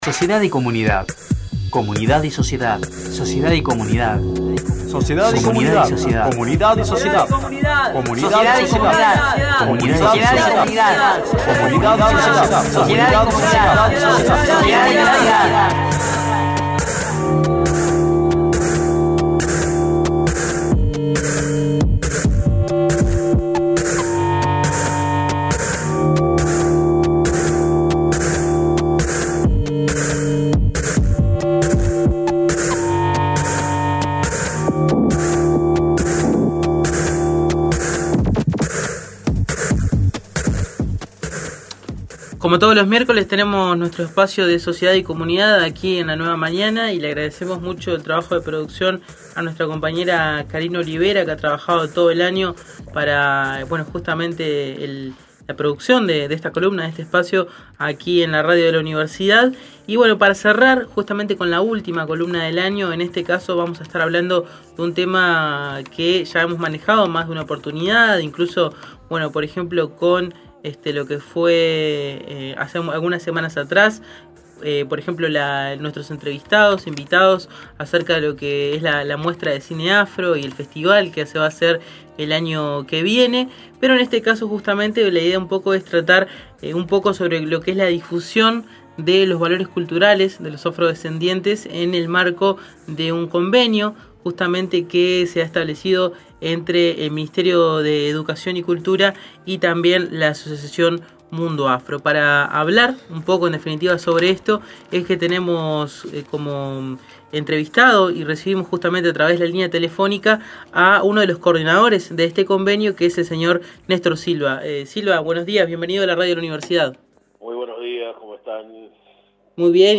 La Nueva Mañana tuvo el pasado 28 de diciembre su úñtimo espacio de Sociedad y Comunidad del ciclo 2011. Para la ocasión, entrevistamos telefónicamente